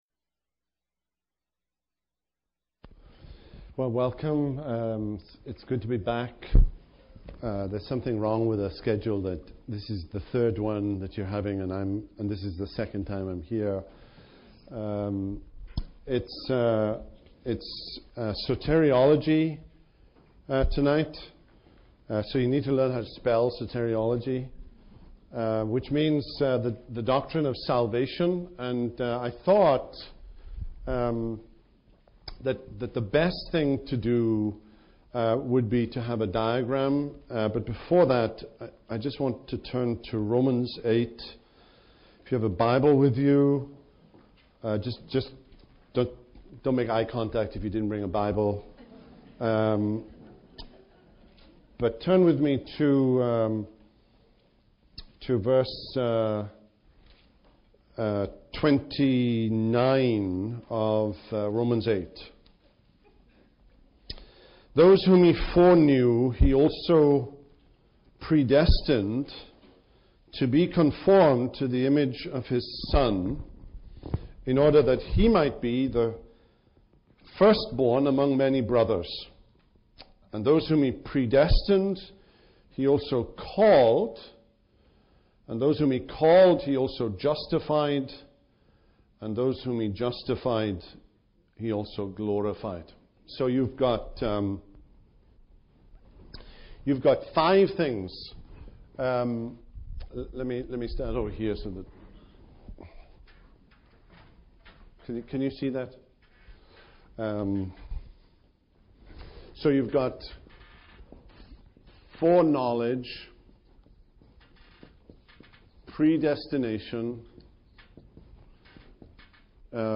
Lecture 1: Theology Proper: Soteriology